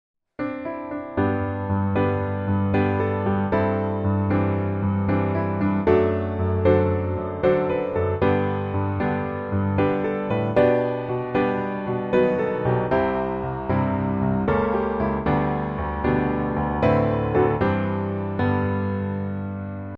G Majeur